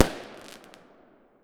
pop3.wav